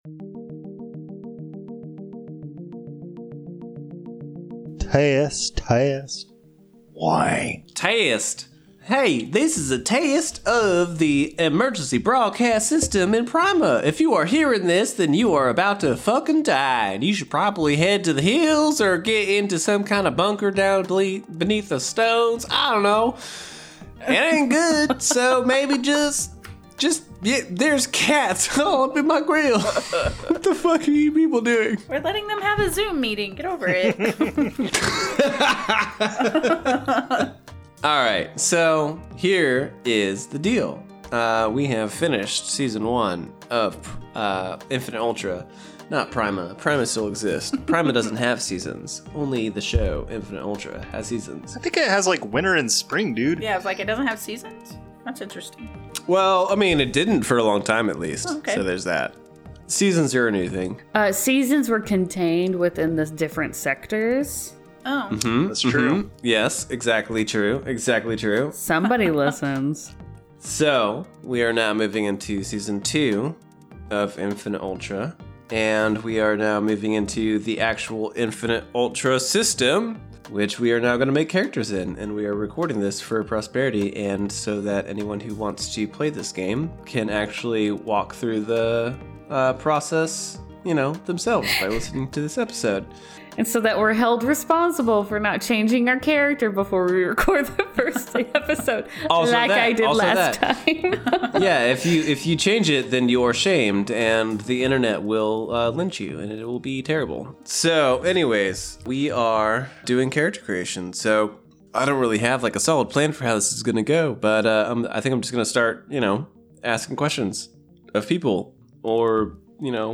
We delve into the Infinite Ultra system as the players build their new characters. Some of it's easy, some of it gets a little weird, and some of it just sounds odd (literally, we had some technical difficulties).